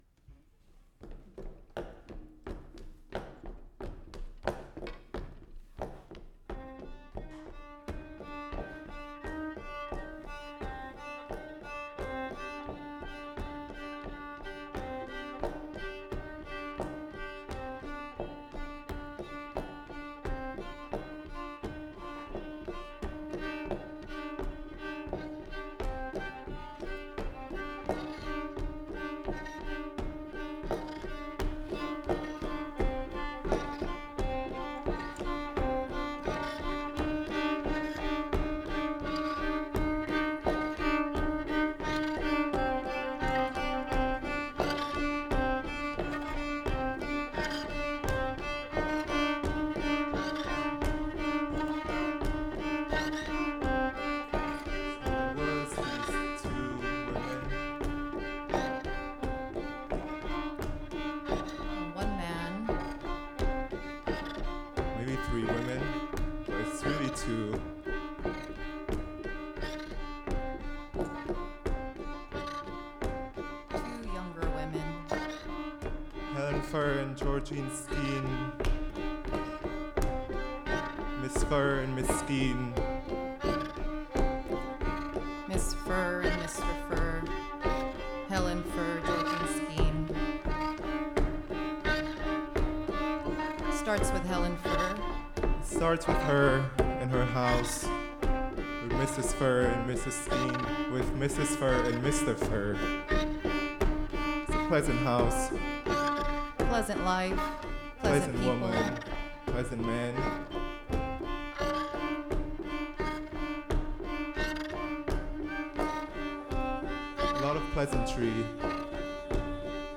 A rare form of Vietnamese chamber music convenes with Western contemporary string music to reanimate Ca Trù's disavowed histories.
This collaboration reanimates the disavowed sexual and social histories of Ca Trù, situating the body as a site of memory and transmission. Through structured improvisation, the artists intertwine the sonic vocabularies of Ca Trù, Western contemporary string music, and experimental dance, allowing divergent traditions to resonate, collide, and transform one another.
A livestream will be available free of charge at 8pm on the day of the performance and archived for future viewing.
viola
percussive clappers (phách)
three-stringed lute (đàn đáy)